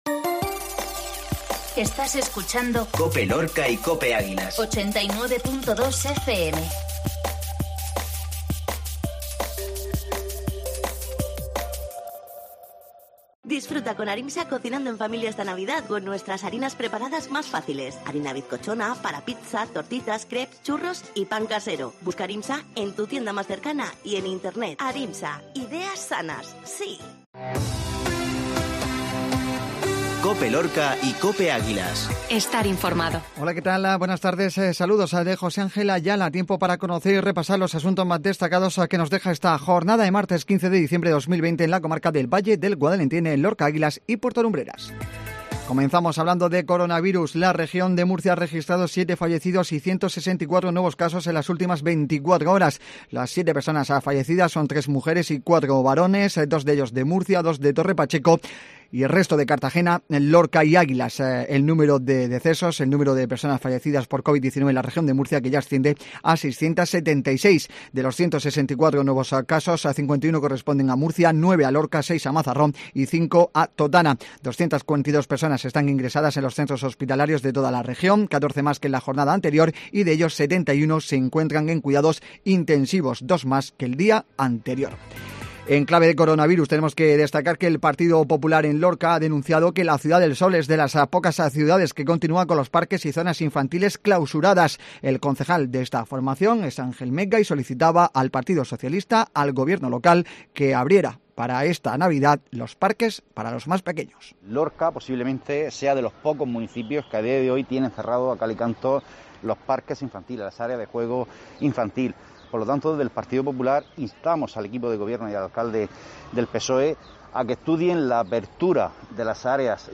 INFORMATIVO MEDIODÍA COPE